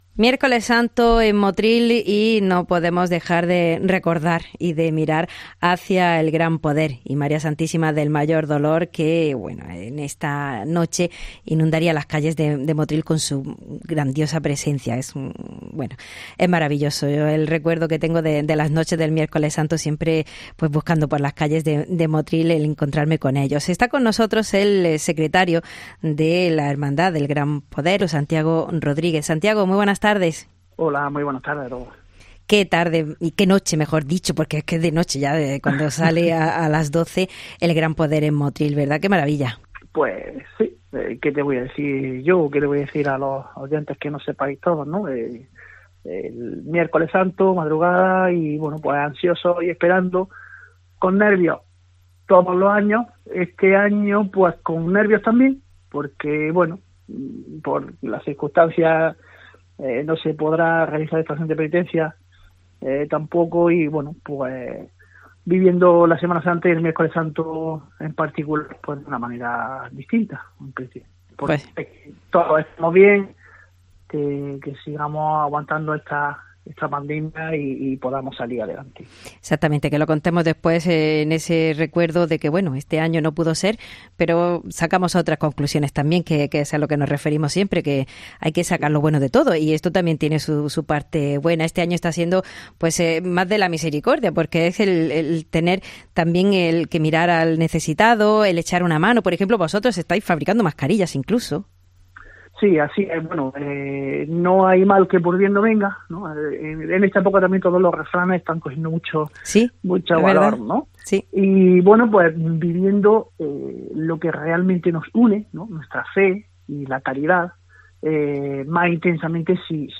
En este Miércoles Santo hablamos con la Fervorosa Hermandad de Penitencia de Ntro. Padre Jesús del Gran Poder y María Santísima del Mayor Dolor de Motril